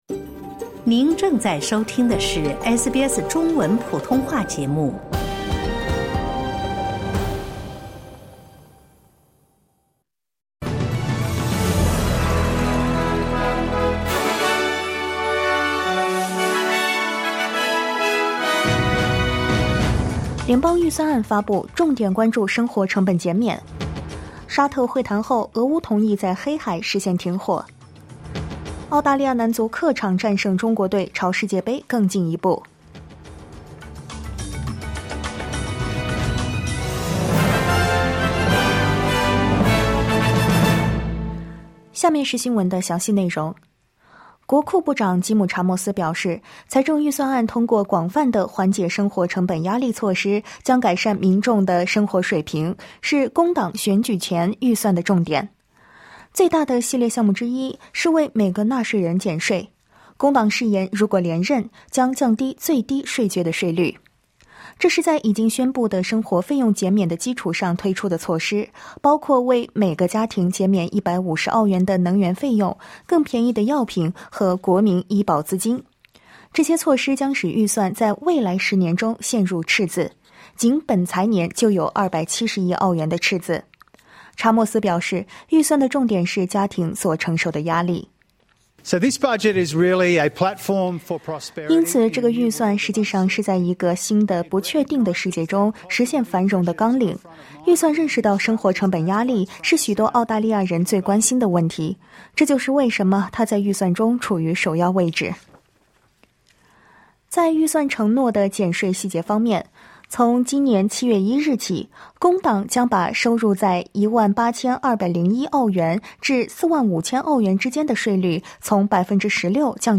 SBS早新闻（2025年3月26日）